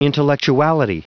Prononciation du mot : intellectuality